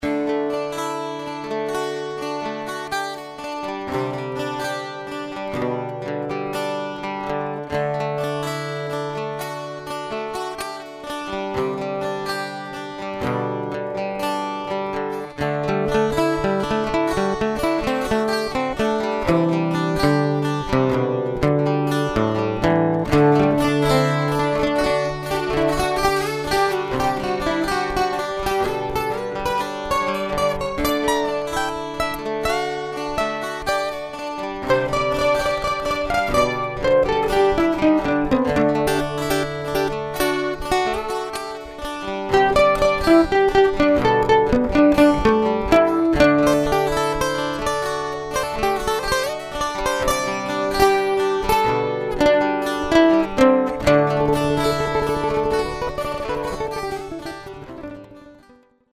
The title doesn't match the mood of the song, as it's more uplifting than the title suggests. A friend said it sounds a lot like some '70s rock song, can't recall which.
Whatever, I liked it and had fun panning the solos left and right.